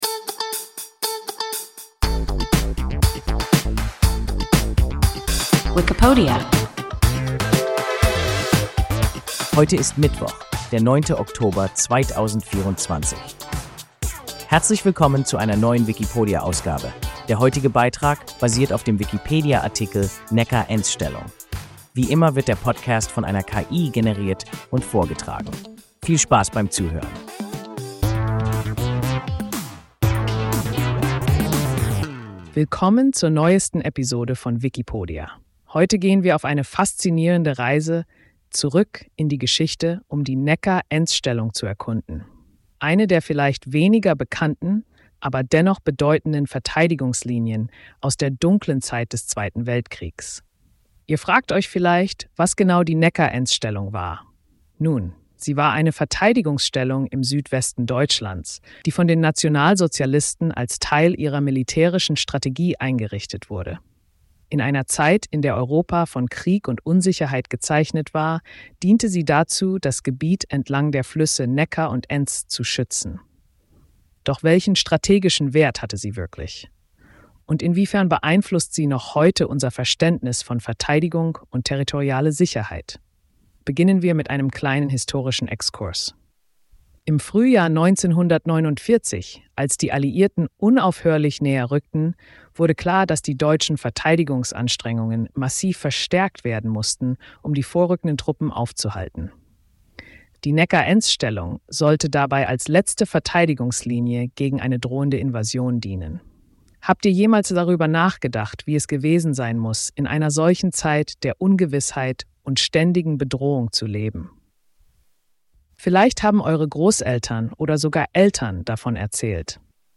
Neckar-Enz-Stellung – WIKIPODIA – ein KI Podcast